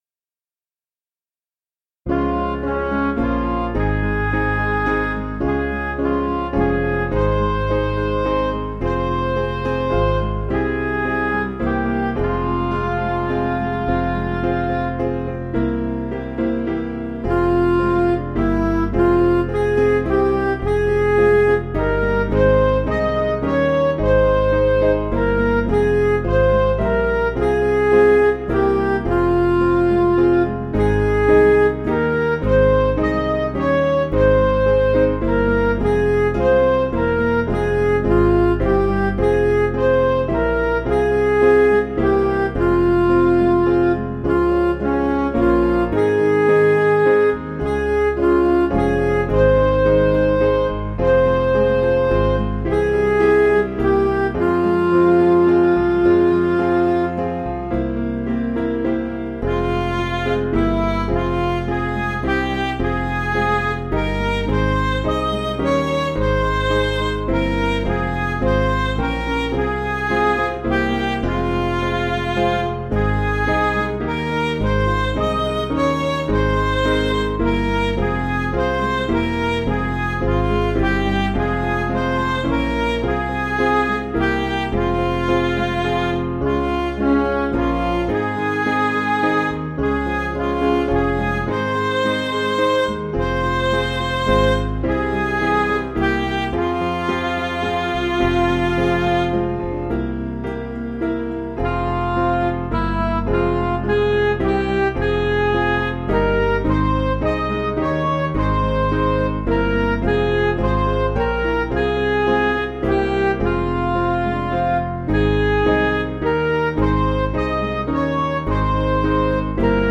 Piano & Instrumental
(CM)   5/Fm